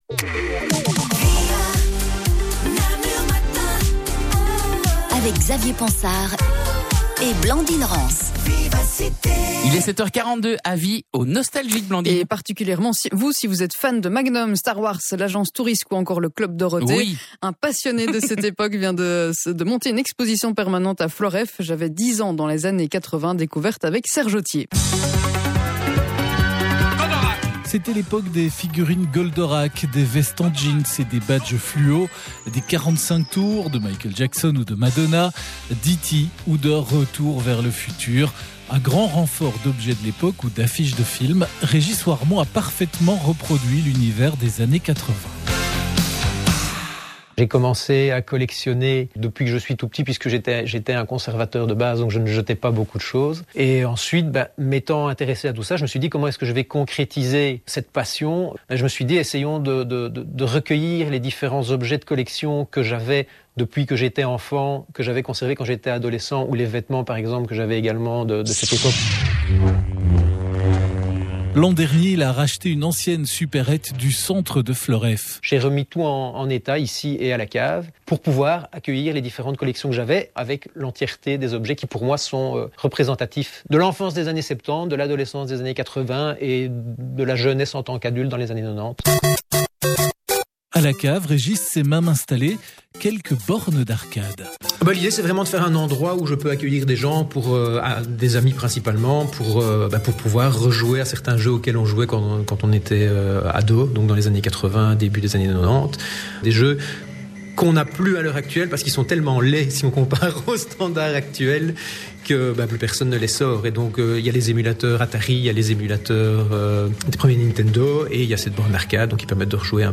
La présentation de l'exposition en radio sur Namur Matin chez Vivacité (12/25) ⤵ MP3 La présentation de l'exposition sur Facebook via la RTBF (12/25) ⤵ !